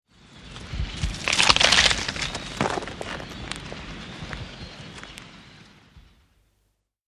Звуки рака, краба
Шум краба в тропических водах